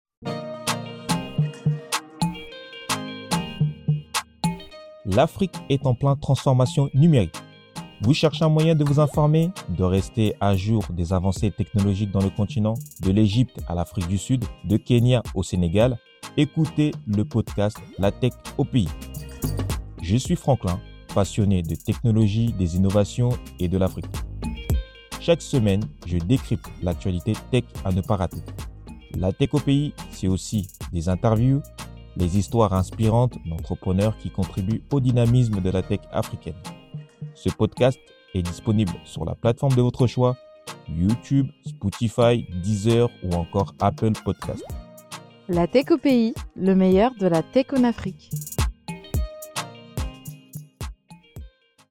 Bande-annoce: Actualités des technogies en Afrique